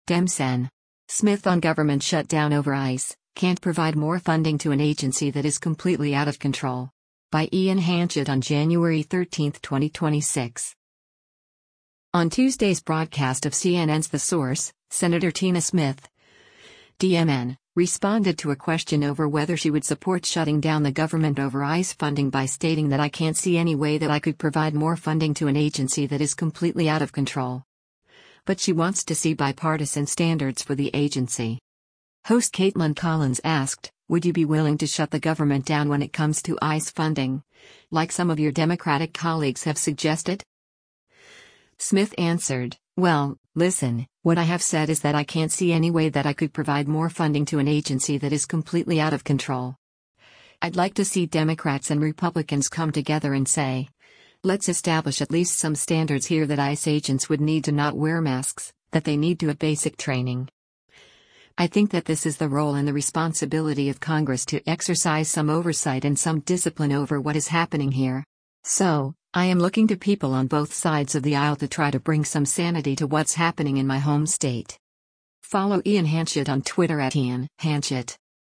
Host Kaitlan Collins asked, “Would you be willing to shut the government down when it comes to ICE funding, like some of your Democratic colleagues have suggested?”